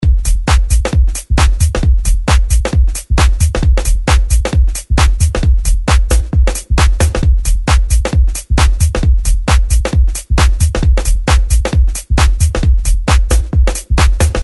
Styl: House, Techno, Minimal
E. Loops 1-17F. Loops 18-35